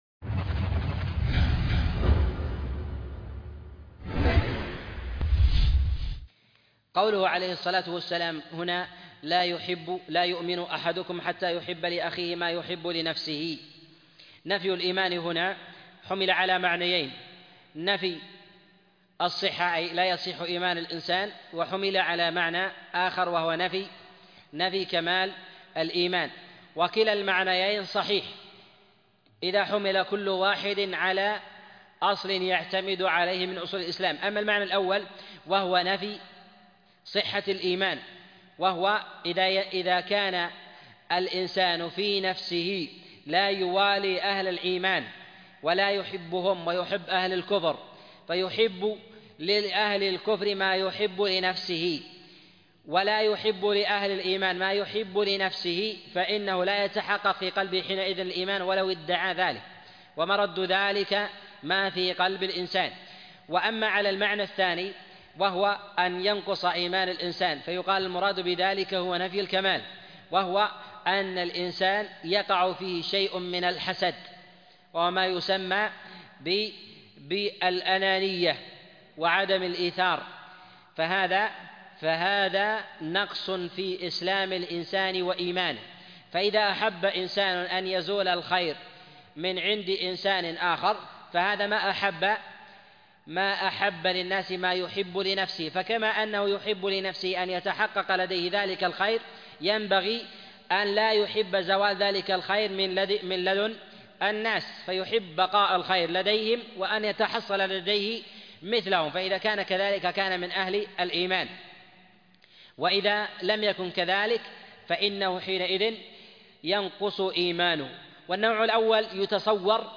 عنوان المادة من شرح الأربعين النووية الدرس 13